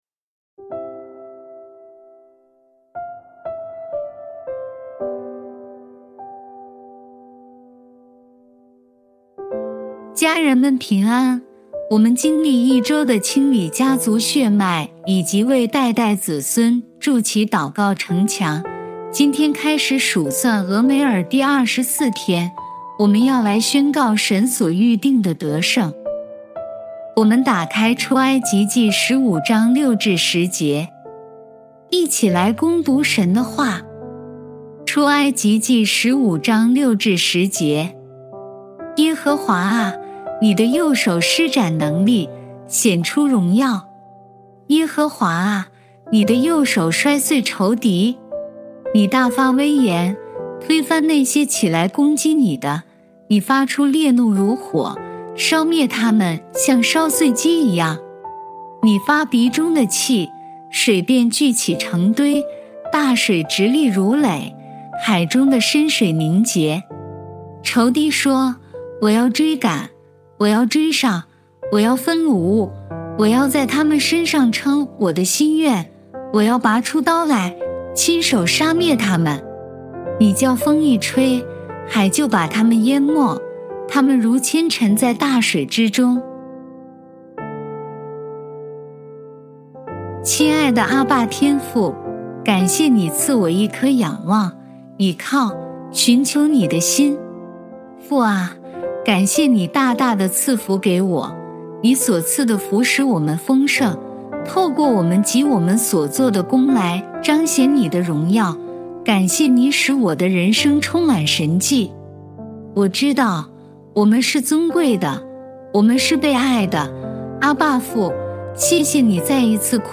祷告及朗读